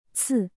c"tsi"